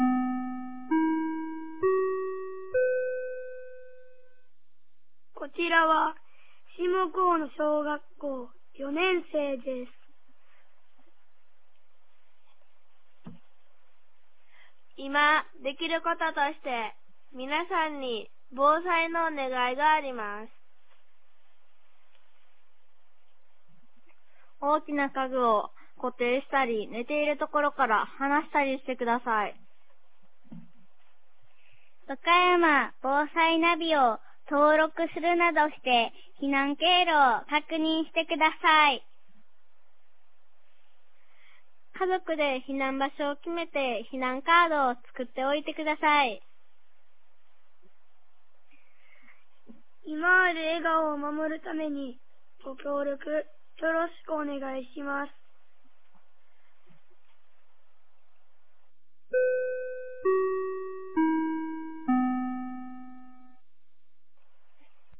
2024年02月12日 15時31分に、紀美野町より全地区へ放送がありました。